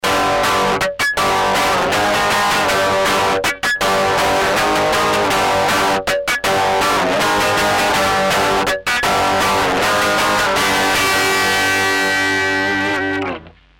takze pustil som vzorku, ktorej posledny obrazok som nechaval do koncaku a boxu a odsnimal som to mikrofonom. v zasade to fungovalo, ale bolo to dost zahuhlane a bez vysok. takze som "od oka" novovzniknuty imulz este trochu vyekvalizoval a tot ukazky:
preamp + impulz sa do istej miery originalu (preamp + koniec + majk) podoba, ale uplne identicke to nie je. predpokladam, ze uplne identicke to asi ani byt nemoze + je zazrak, ze takymto diletantskym sposobom (dodatocna ekvalizacia), ako som to riesil to vobec nejak funguje :)